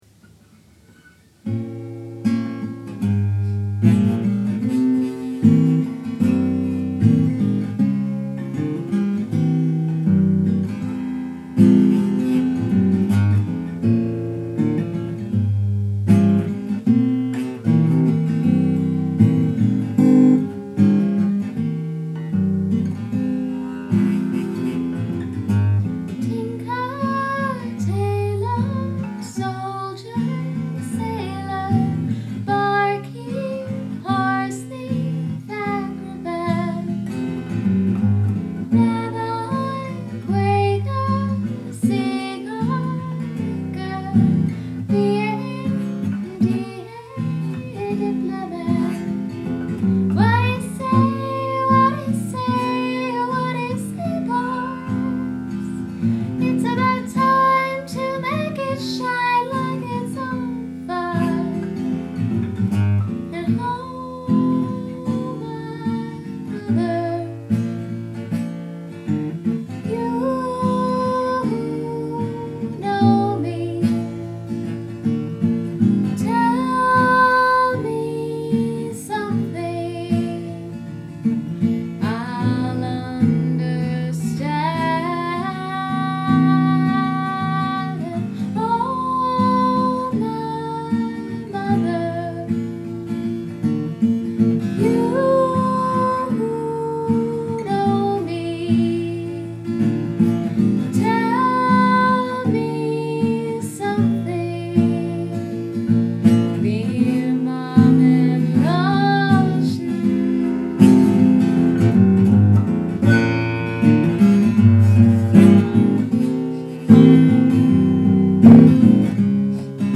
In the mother toungue (demo) - MP3
a a/C B7 C B7 e repeat